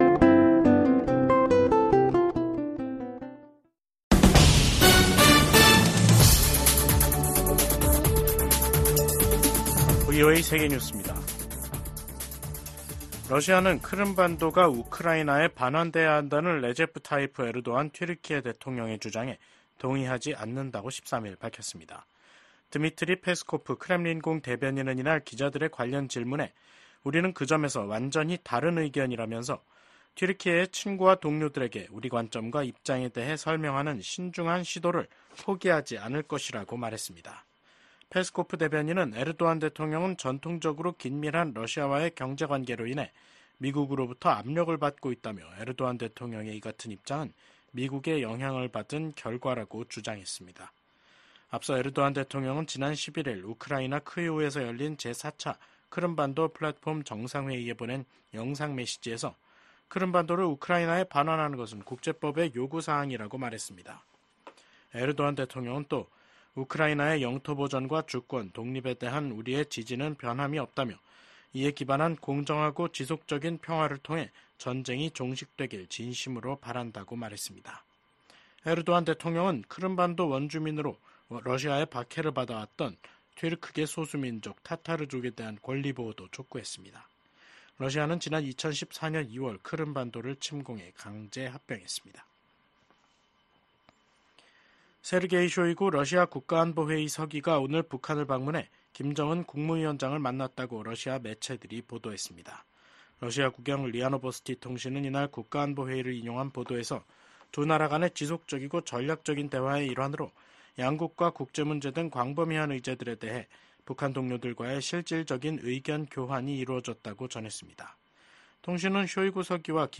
VOA 한국어 간판 뉴스 프로그램 '뉴스 투데이', 2024년 9월 13일 3부 방송입니다. 북한이 핵탄두를 만드는 데 쓰이는 고농축 우라늄(HEU) 제조시설을 처음 공개했습니다. 미국과 리투아니아가 인도태평양 지역에 대한 고위급 대화를 개최하고 러시아의 북한제 탄도미사일 사용을 비판했습니다.